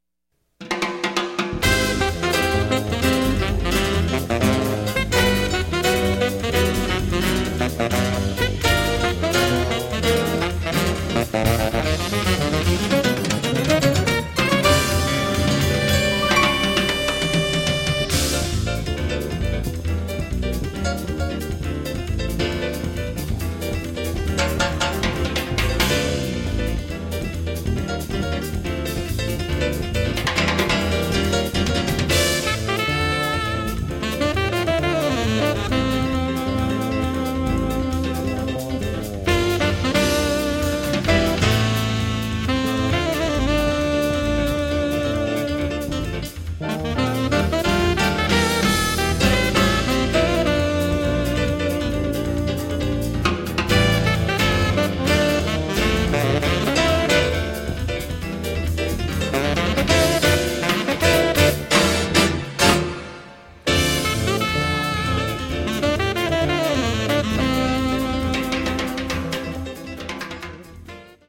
piano and synths
saxophone
bass
drums